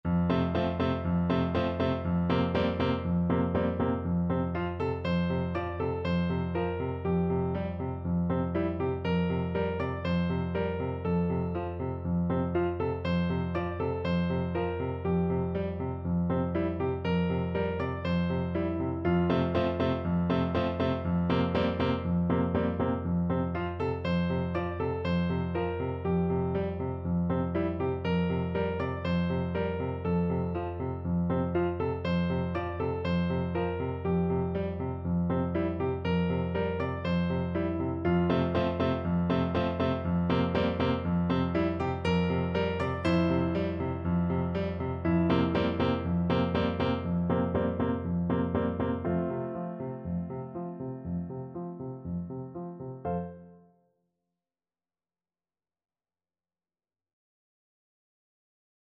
Piano version
No parts available for this pieces as it is for solo piano.
2/4 (View more 2/4 Music)
F major (Sounding Pitch) (View more F major Music for Piano )
Lively, enthusiastic! =c.120
Piano  (View more Intermediate Piano Music)
Traditional (View more Traditional Piano Music)
bangun_tidur_PNO.mp3